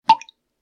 drip.ogg.mp3